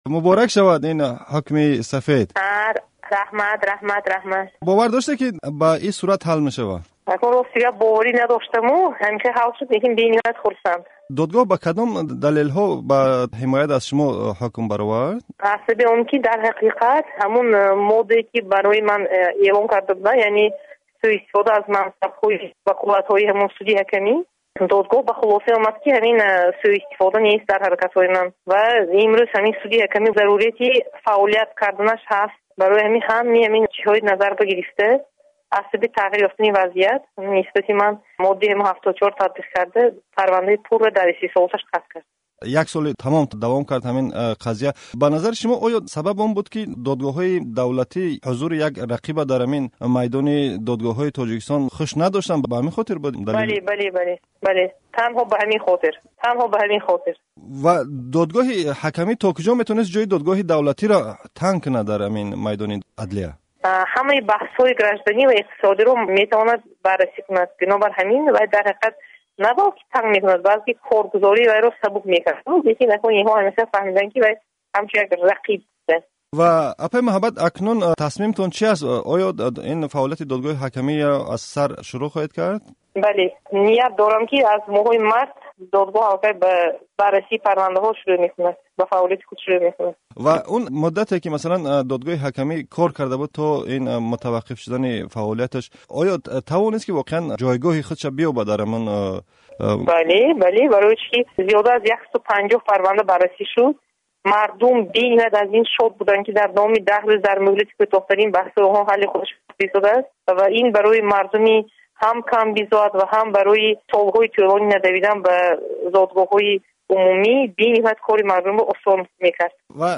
Гуфтугӯ